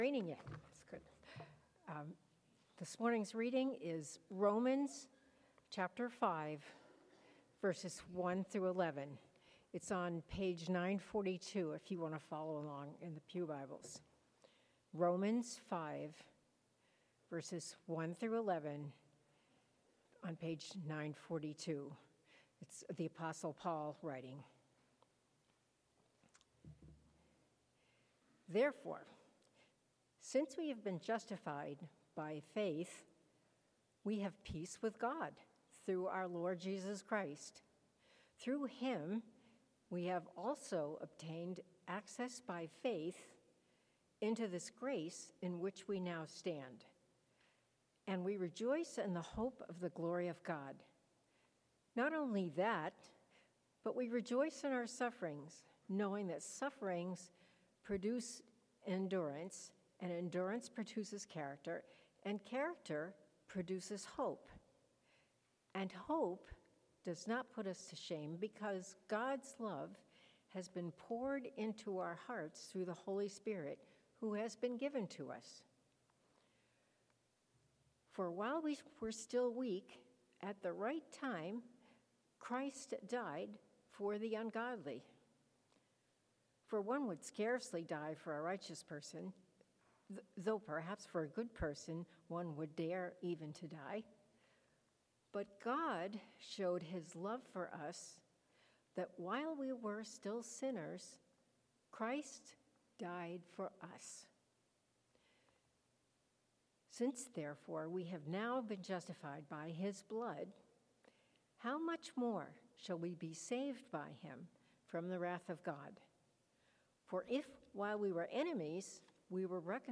Passage: Romans 5:1-11 Sermon